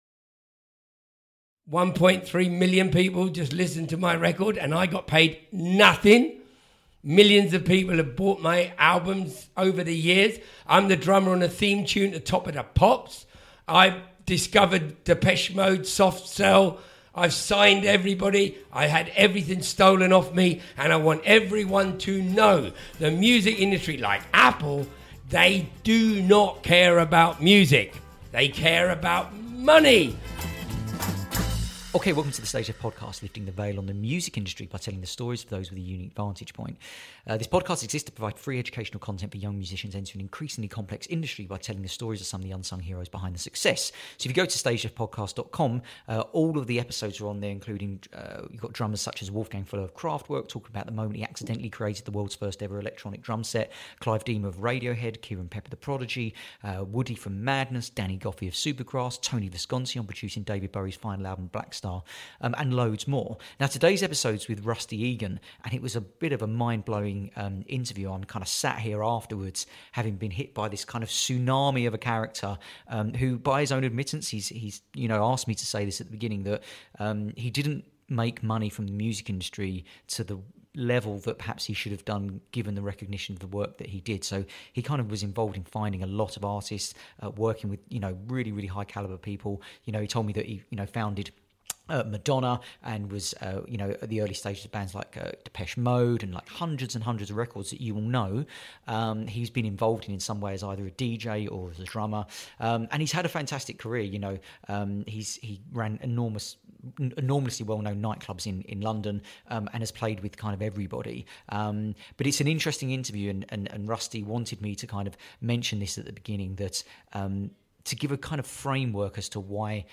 An extraordinary episode of the The StageLeft Podcast with legendary drummer and DJ Rusty Egan.